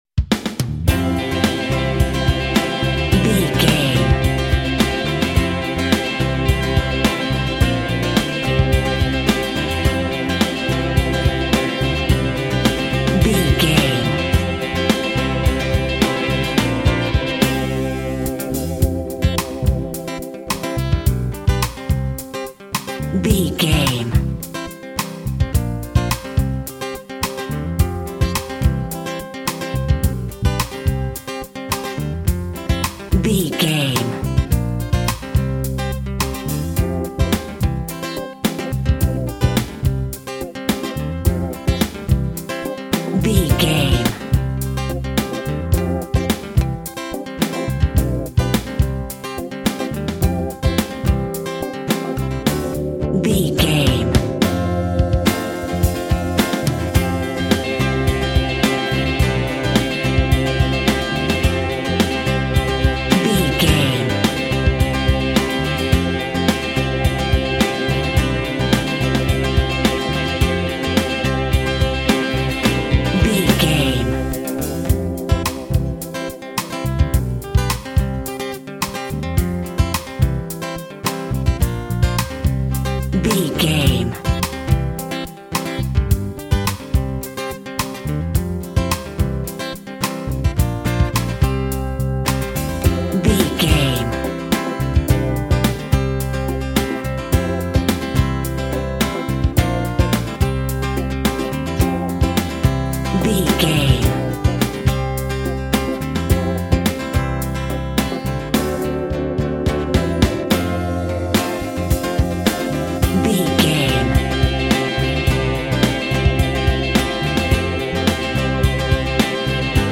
Boy Band Music.
Ionian/Major
pop
cheesy
Teen pop
electro pop
Power pop
pop rock
drums
bass guitar
electric guitar
piano
hammond organ